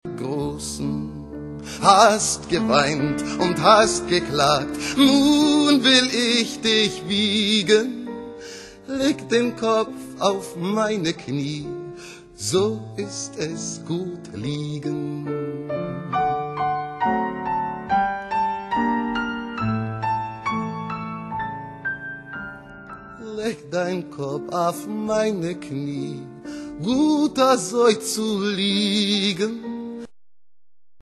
Klavier
Violine, Gesang